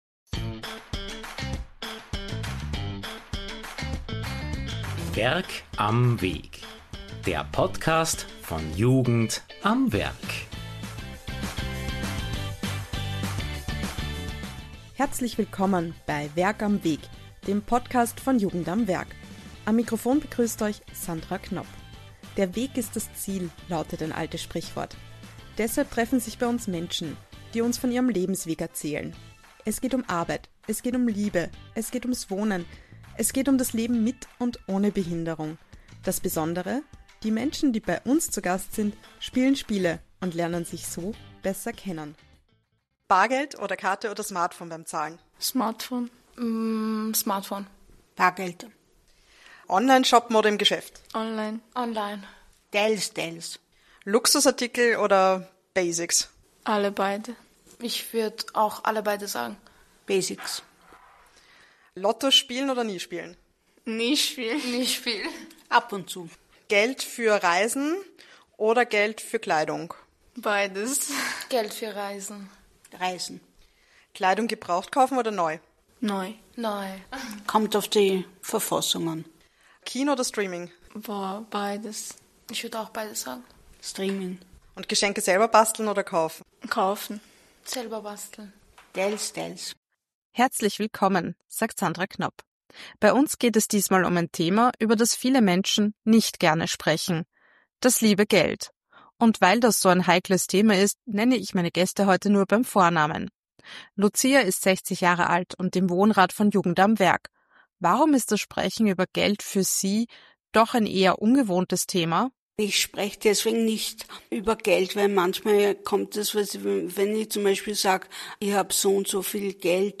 Natürlich wird in unserem Begegnungspodcast dann auch wieder gespielt!